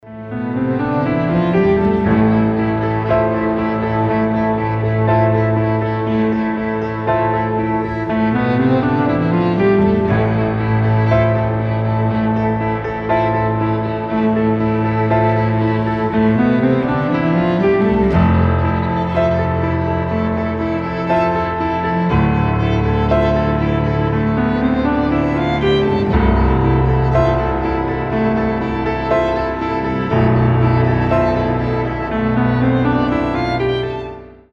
• Качество: 320, Stereo
грустные
спокойные
без слов
оркестр
тревога